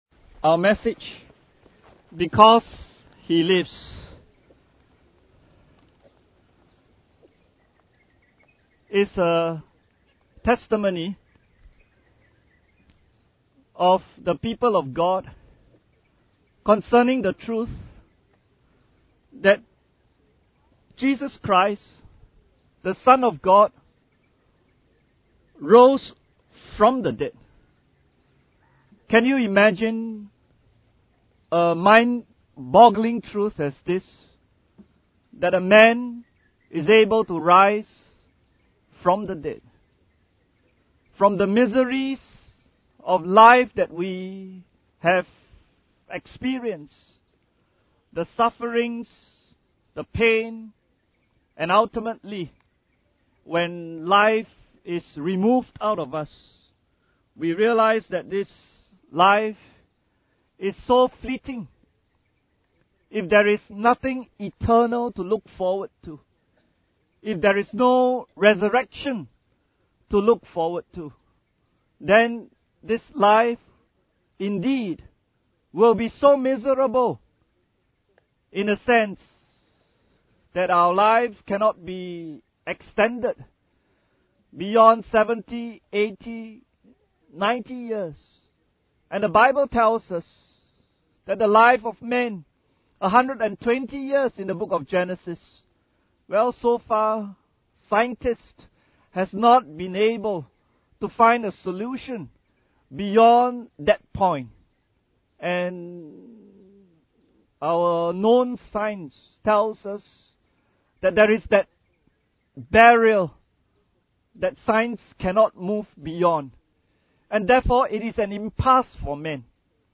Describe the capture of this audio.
Easter Sunrise Service 2017 – Because He Lives